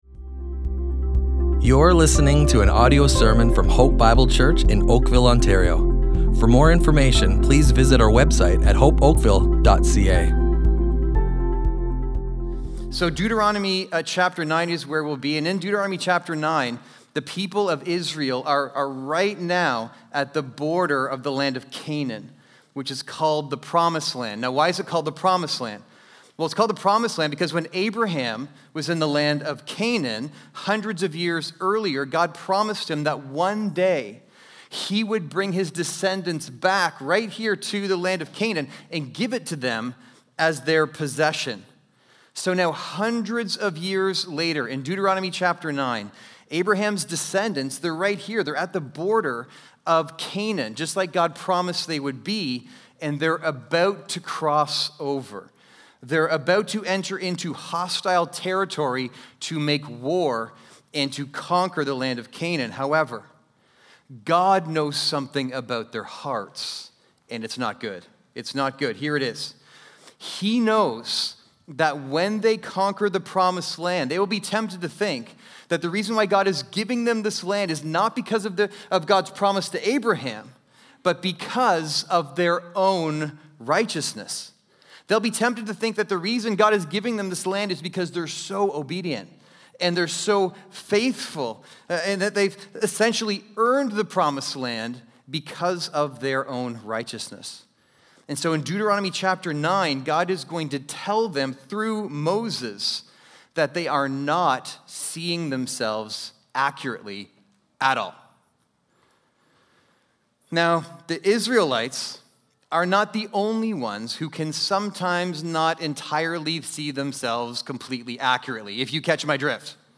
Hope Bible Church Oakville Audio Sermons Listen and Love // See Yourself Accurately Jun 01 2025 | 00:45:04 1x 00:00 / 00:45:04 Subscribe Share